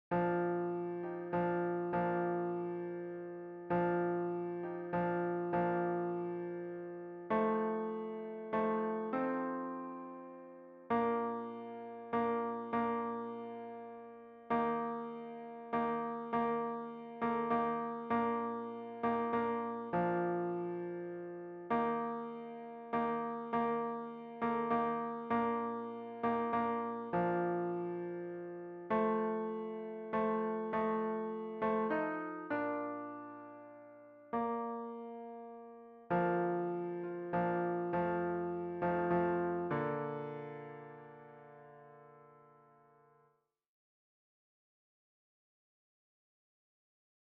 Fichiers pour répéter :
Douce nuit tenor